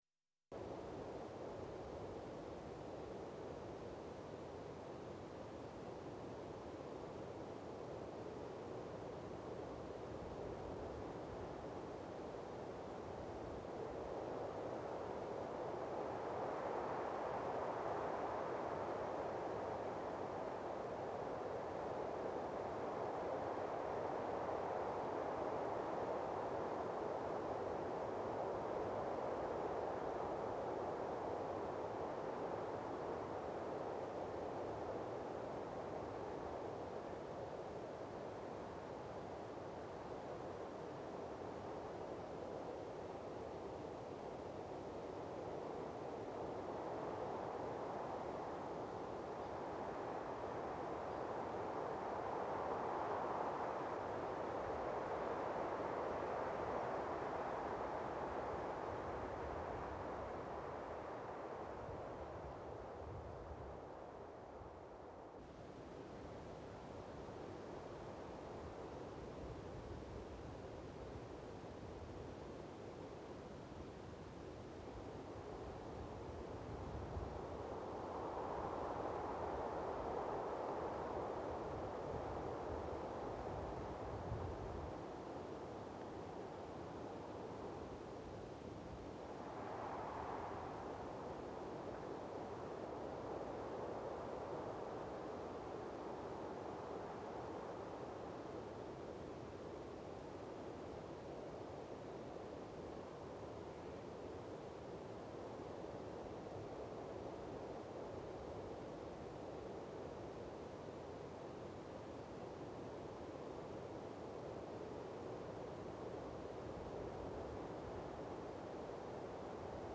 Wind Light Steady Wind.wav